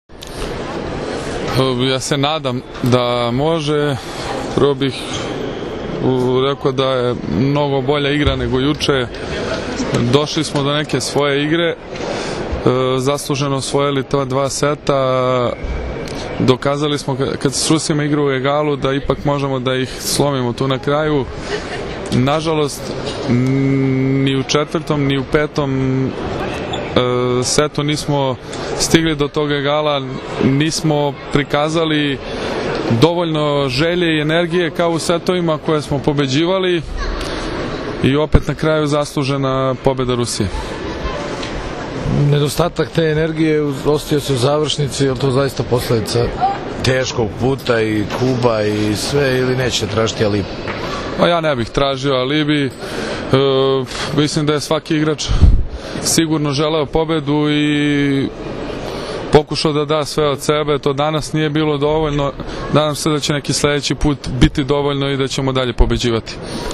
IZJAVA NIKOLE ROSIĆA